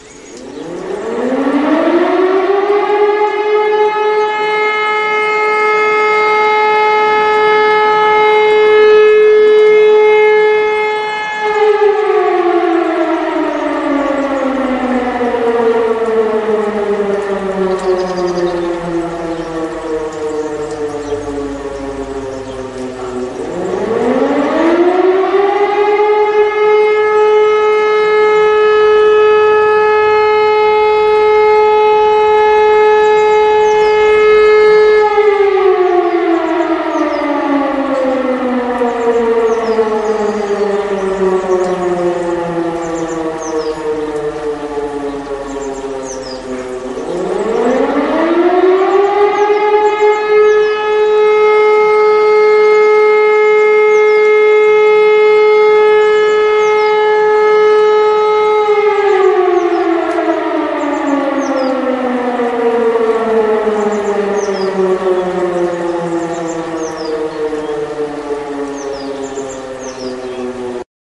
Sehr oft wurde das Sirenenmodell E-57 eingesetzt.
Sirenenprobe_Luftschutzsirene_E57.mp3